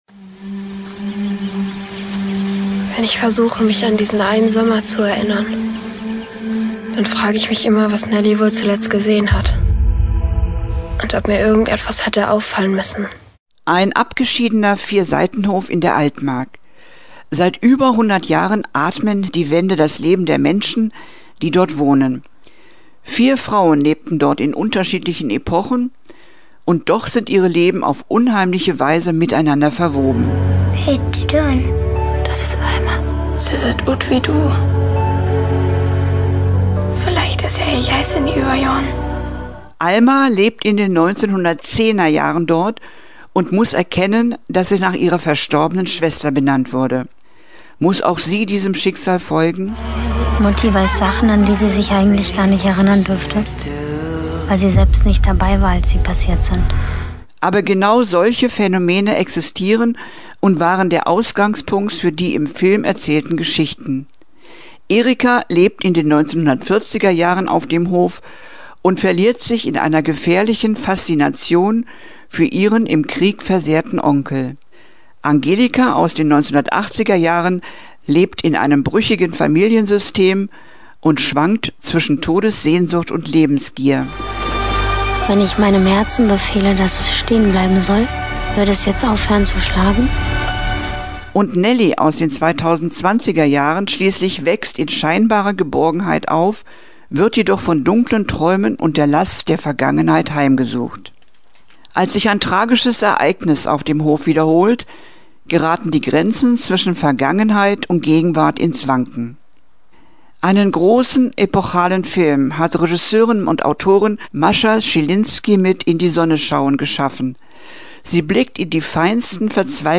Filmtipp der Woche: „In die Sonne schauen“ - Okerwelle 104.6